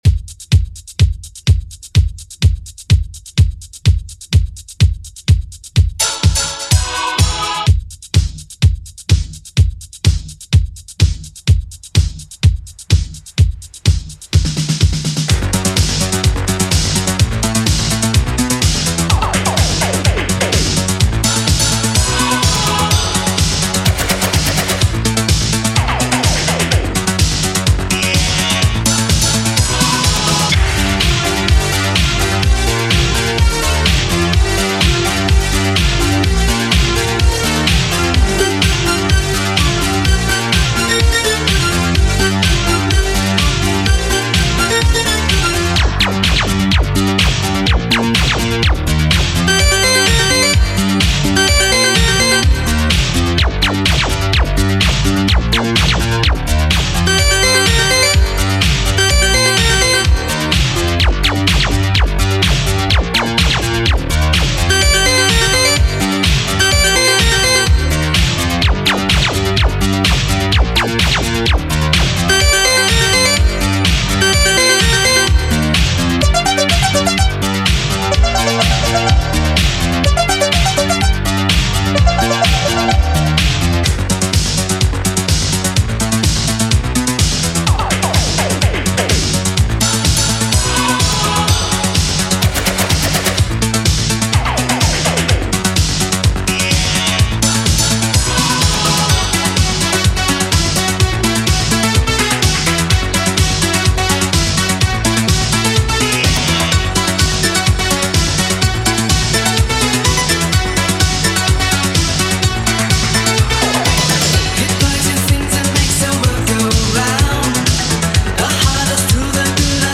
Genre: 80's
BPM: 123